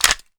ak74_magout.wav